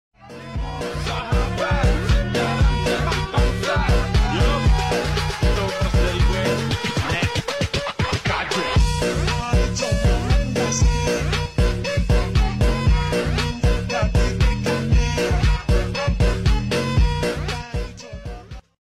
mainecoon cat